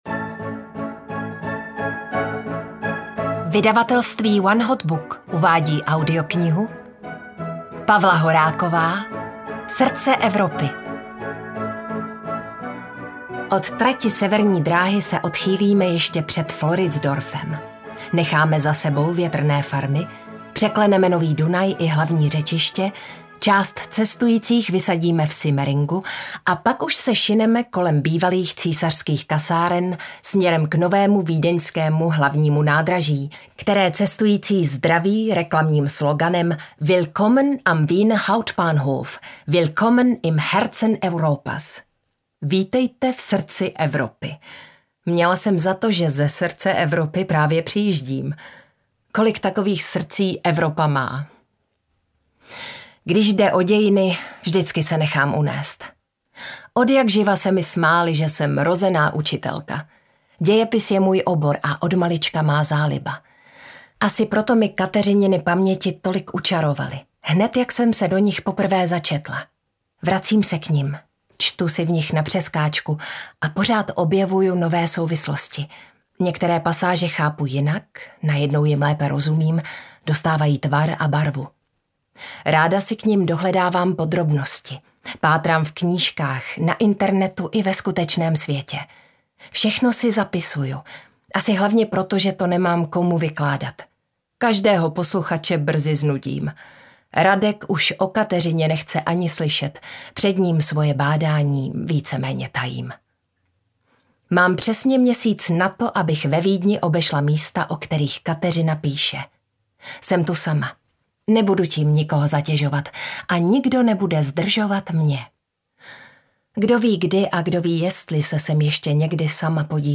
Srdce Evropy audiokniha
Pozoruhodný dialog dvou vyprávěcích hlasů, které dělí více než sto let.
Ukázka z knihy